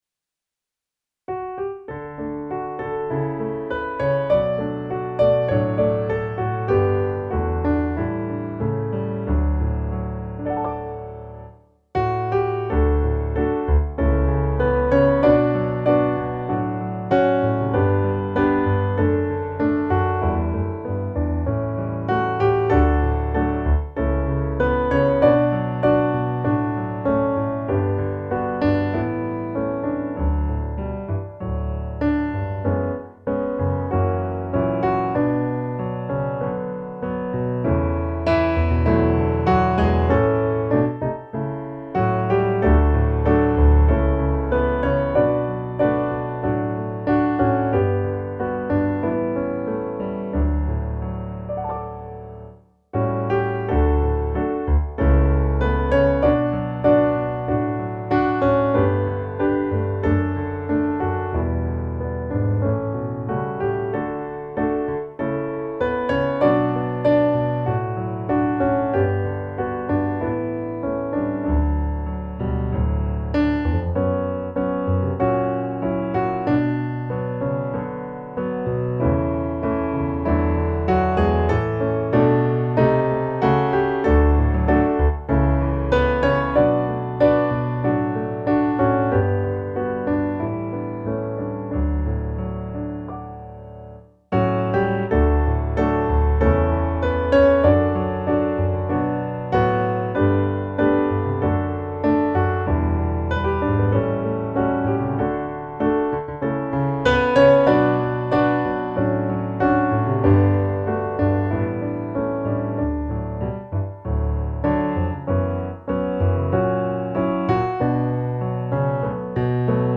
Gemensam sång
Musikbakgrund Psalm